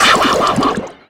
Cri de Denticrisse dans Pokémon Soleil et Lune.